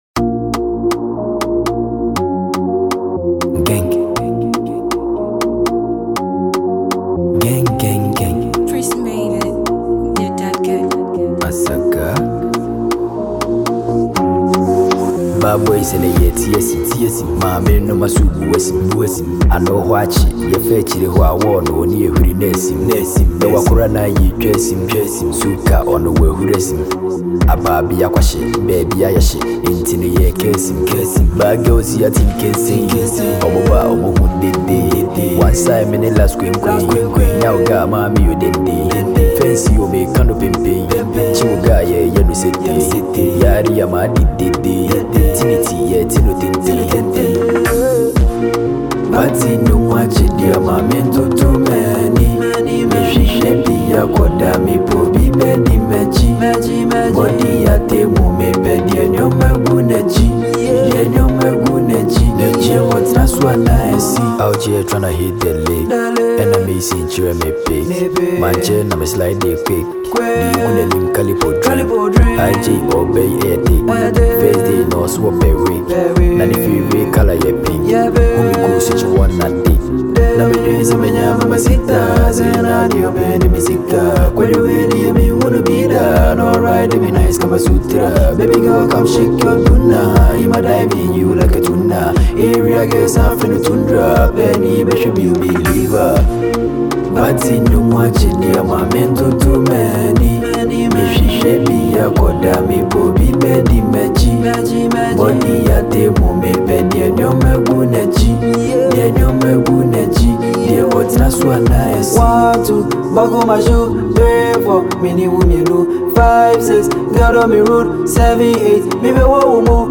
a Ghanaian asakaa rapper